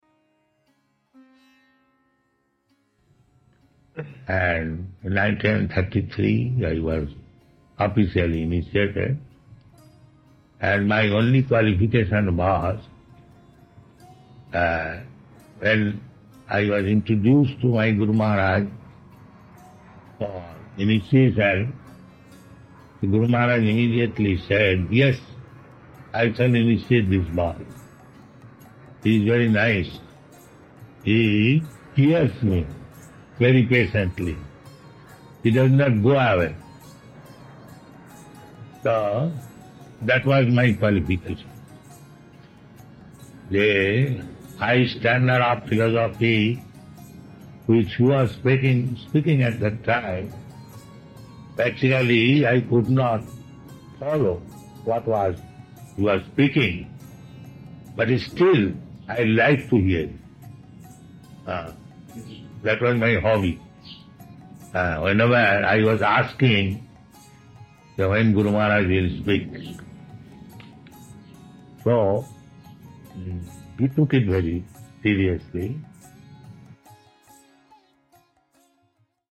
(761210 - Lecture Festival Disappearance Day, Bhaktisiddhanta Sarasvati - Hyderabad)